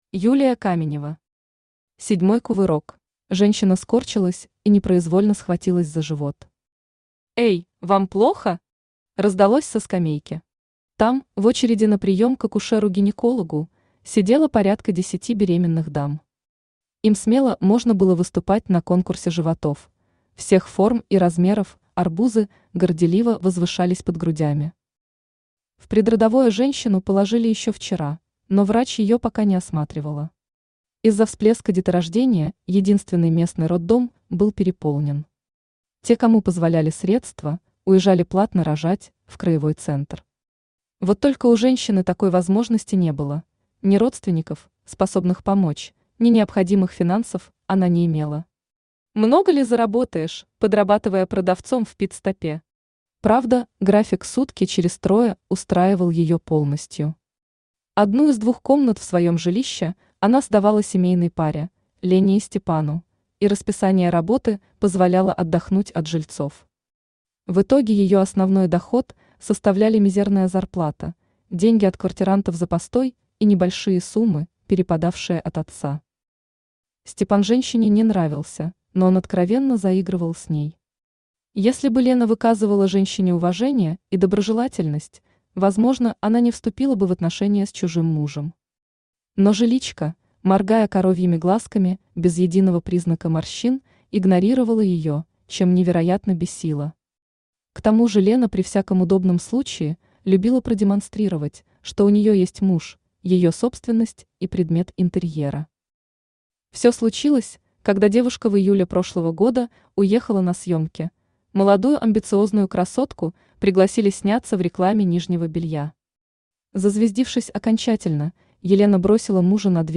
Аудиокнига Седьмой кувырок | Библиотека аудиокниг
Aудиокнига Седьмой кувырок Автор Юлия Каменева Читает аудиокнигу Авточтец ЛитРес.